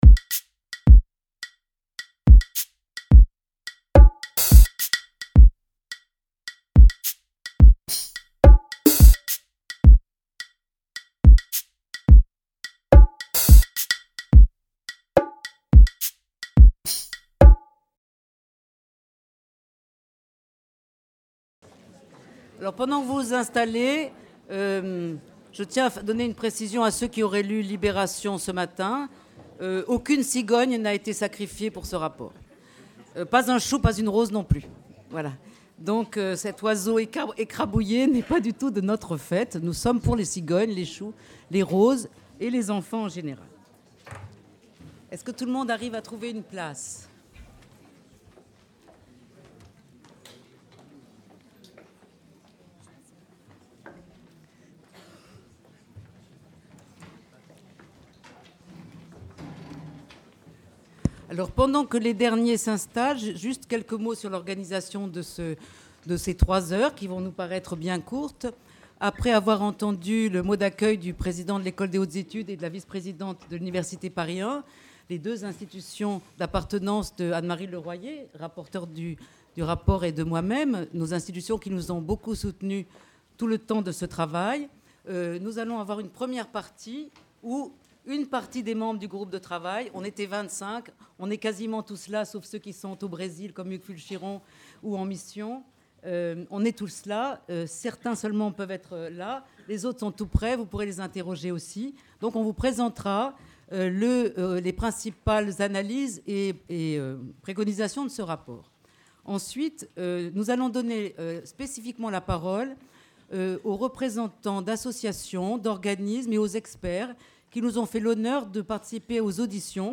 Un besoin de réformes Conférence organisée par les Cercles de formation de l'EHESS Présentation du rapport remis à la ministre déléguée chargée de la famille
En présence de représentants des experts et associations auditionnés. Conférence-débat organisée par les Cercles de formation de l’EHESS et l'Institut de recherche juridique de la Sorbonne (IRJA) Université Paris 1.